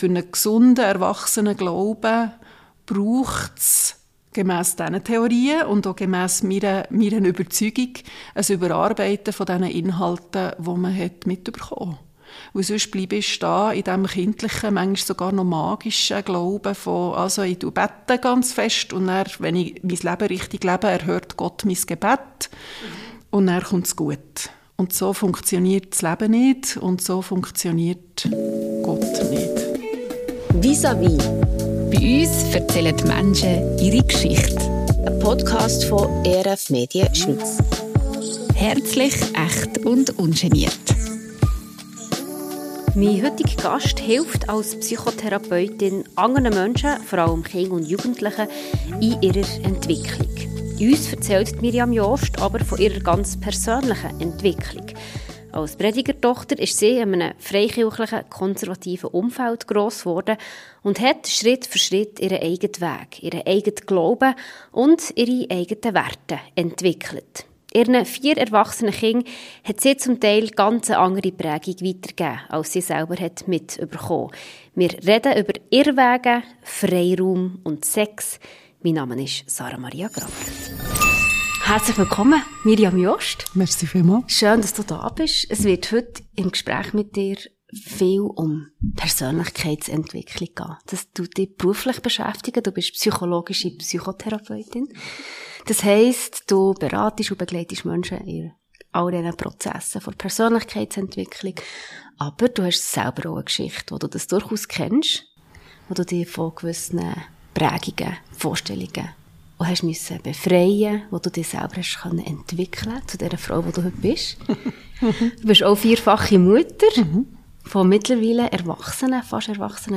Ein Gespräch über Irrwege, Freiraum und Sex.